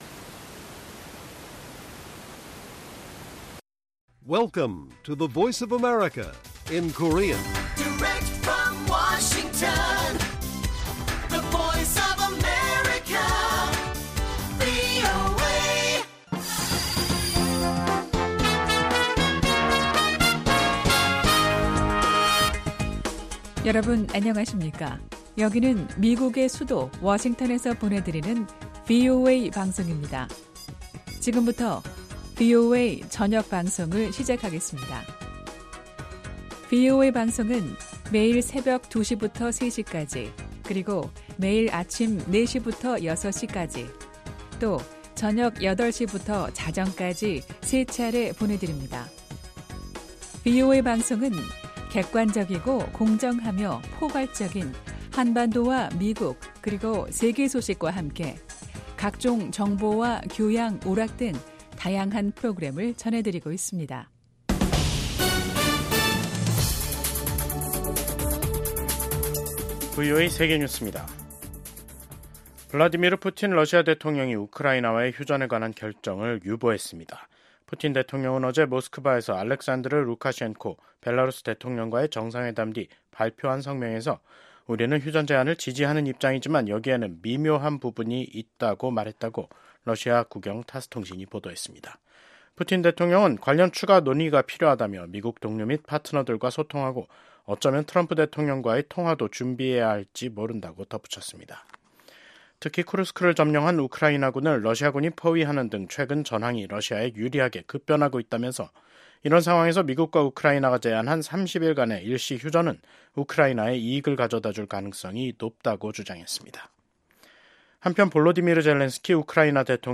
VOA 한국어 간판 뉴스 프로그램 '뉴스 투데이', 2025년 3월 14일 1부 방송입니다. 도널드 트럼프 미국 대통령이 또다시 북한을 ‘뉴클리어 파워’ 즉 ‘핵 국가’로 지칭했습니다. 일본 주재 미국 대사 지명자가 미한일 3국 협력 강화가 필수적이라며 지속적인 노력이 필요하다고 강조했습니다. 지난 10년 동안 북한 선박 8척이 중국해역 등에서 침몰한 것으로 나타났습니다.